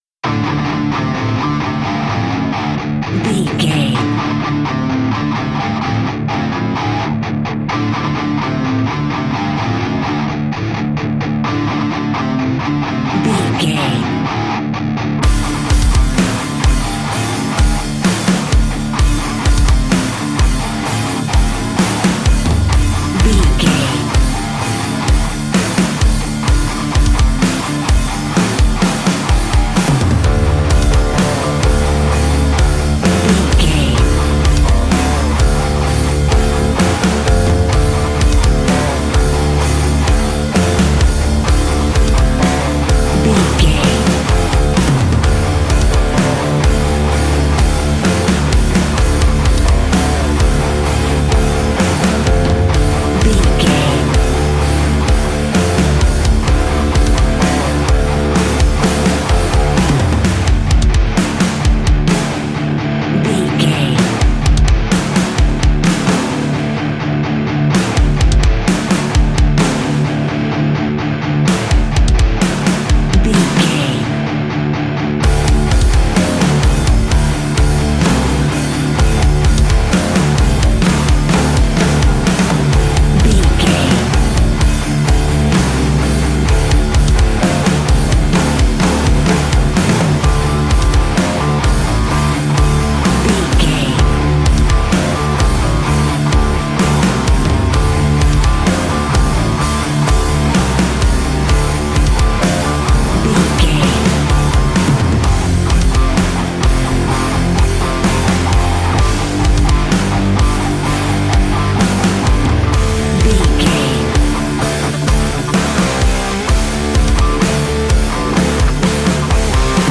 Ionian/Major
D
hard rock
heavy rock
guitars
heavy metal
instrumentals